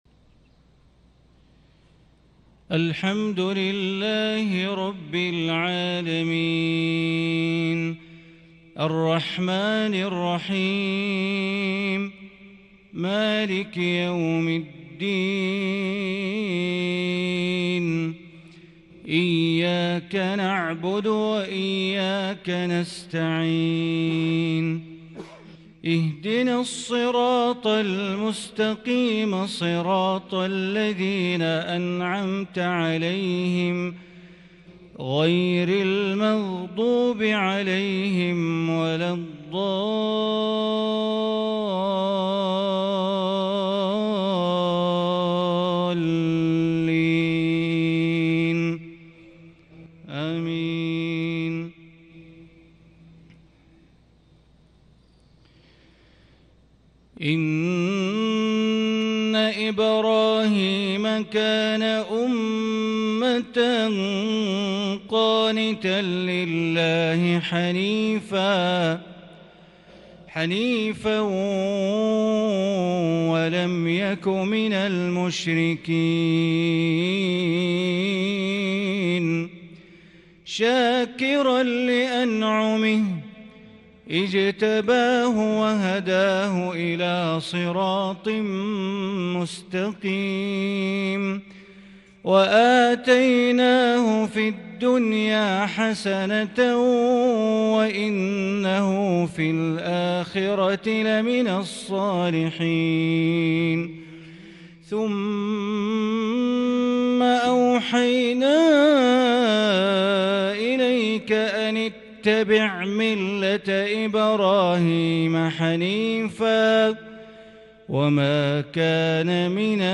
صلاة العشاء 8-2-1442 تلاوة من سورة النحل > 1442 هـ > الفروض - تلاوات بندر بليلة